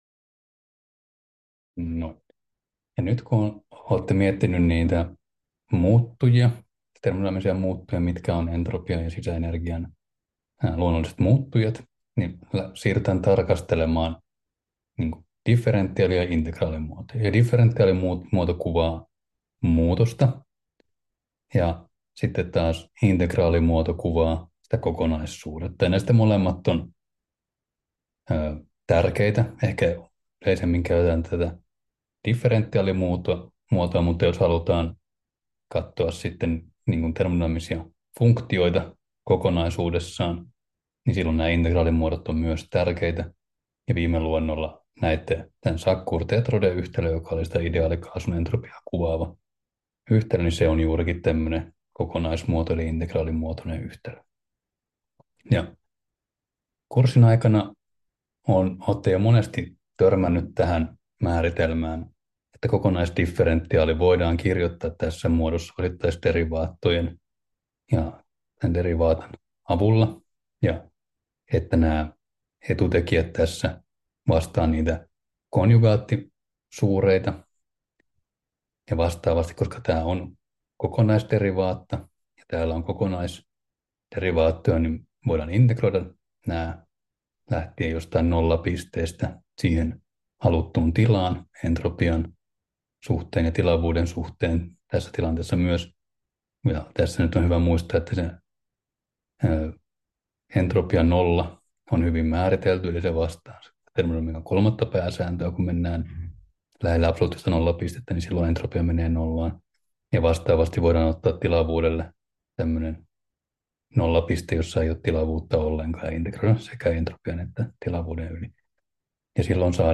Luento 6: Vapaa energia 3 — Moniviestin